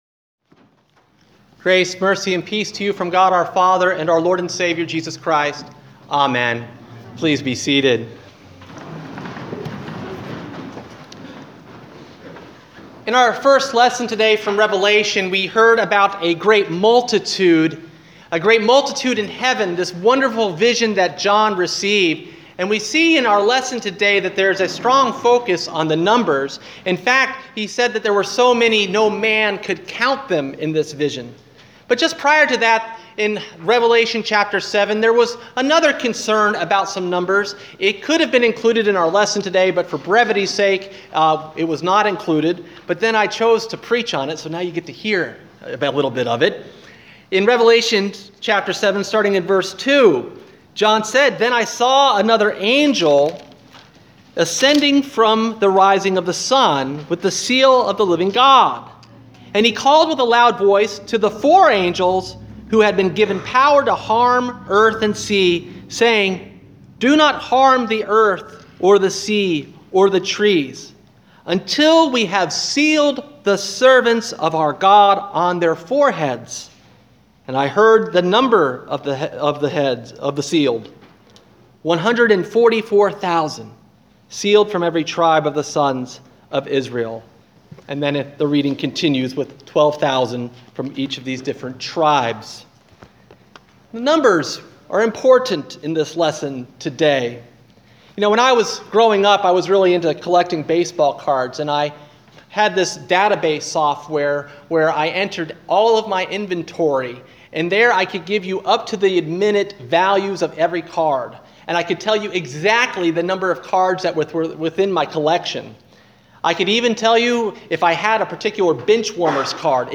Sermon: All Saints Sunday